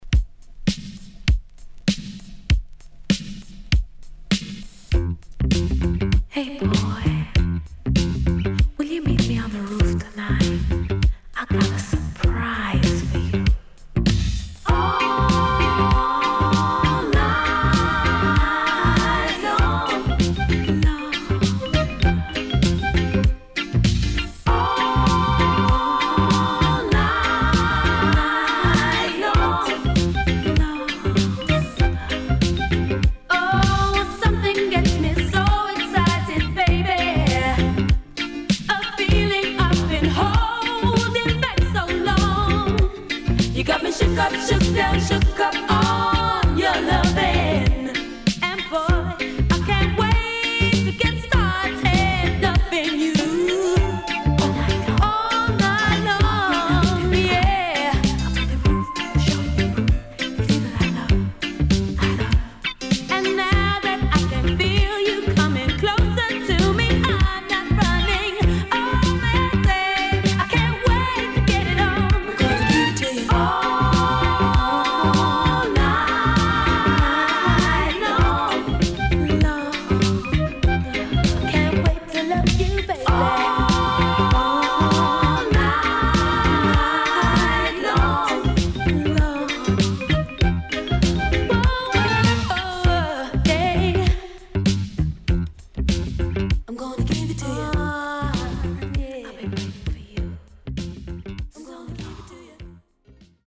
Dubwise